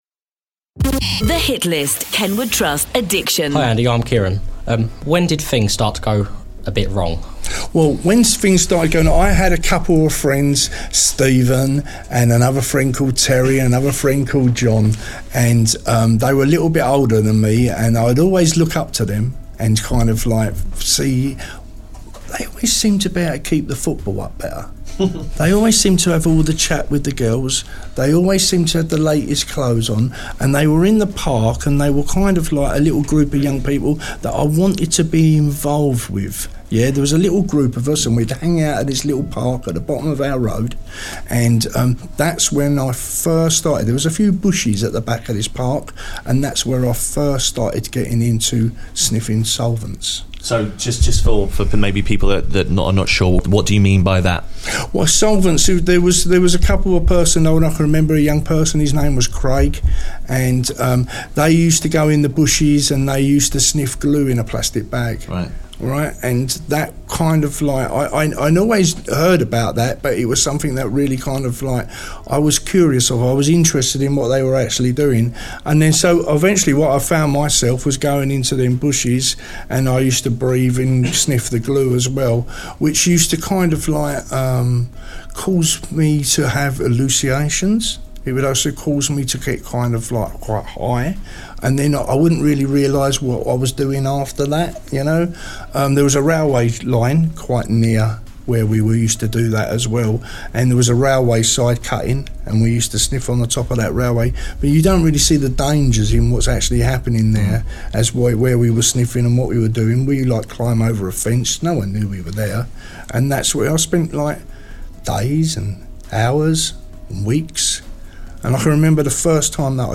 We hosted a panel style session with students from across Kent